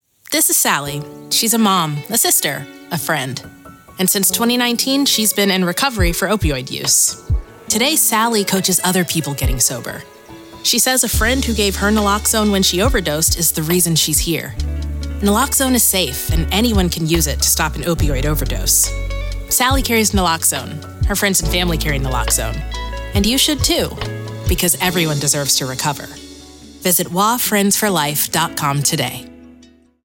Carry Naloxone Audio PSA